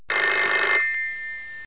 telefon.mp3